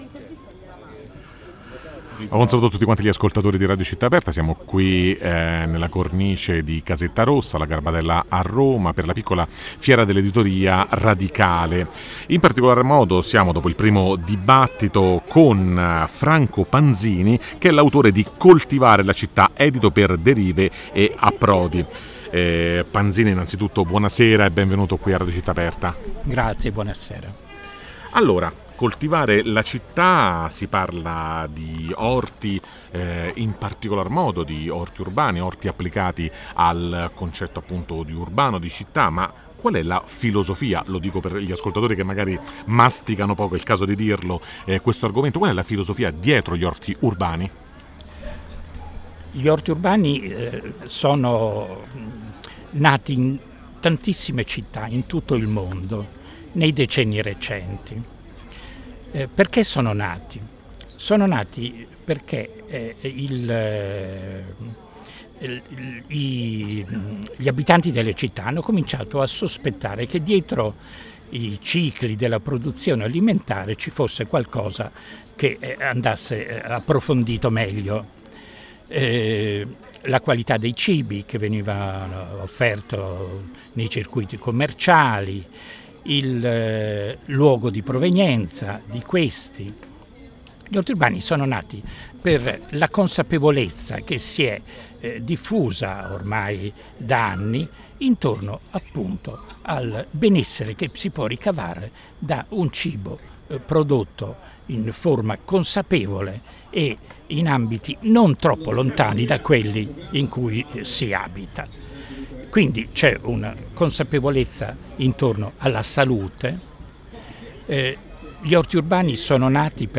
Il 28 – 29 e 30 settembre 2021, nella sede di Casetta Rossa al quartiere Garbatella di Roma, si è tenuta un’importante manifestazione dell’editoria indipendente e “radicale”.